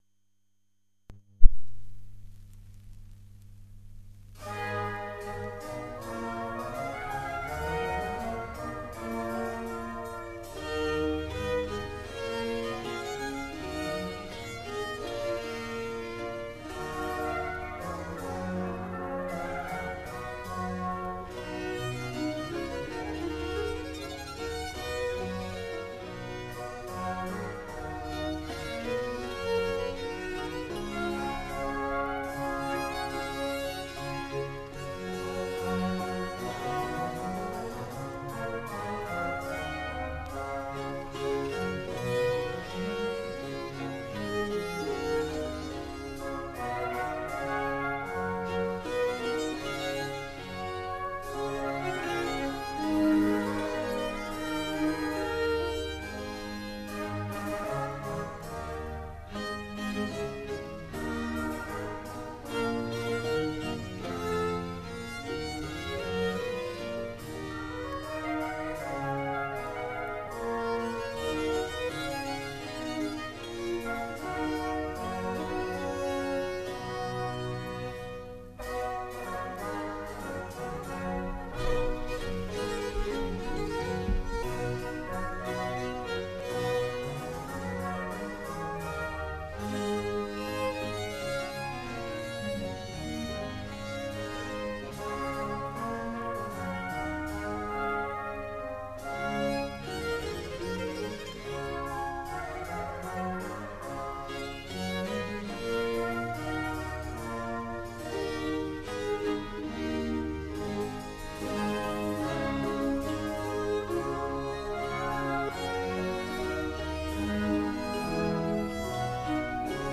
Guami, Canzon a 8 (a 2 cori).mp3